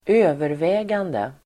Uttal: [²'ö:vervä:gande]